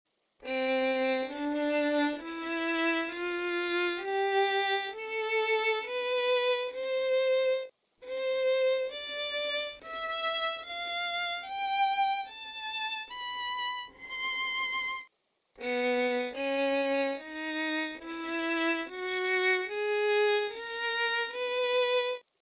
Lonely_Mountain_Fiddle_Sound.mp3